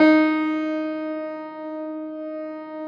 53g-pno11-D2.wav